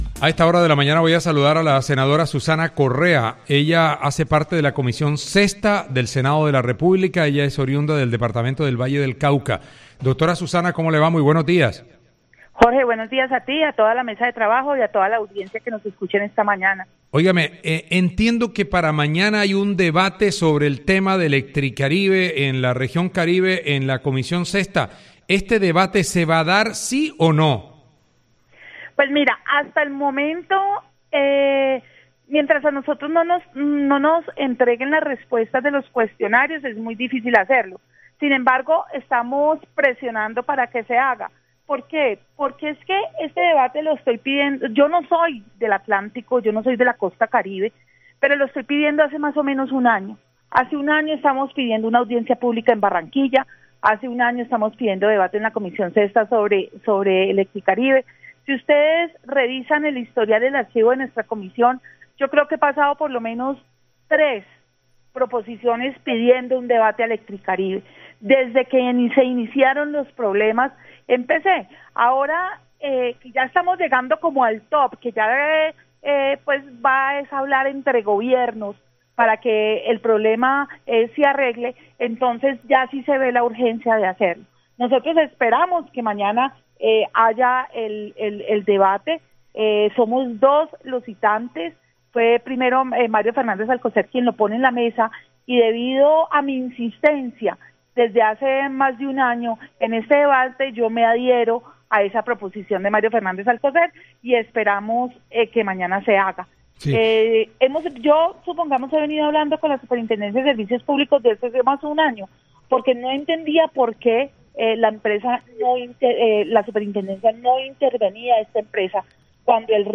La senadora Susana Correa expresó que espera que se realice mañana el debate de control político contra la Empresa Electricaribe en la comisión sexta.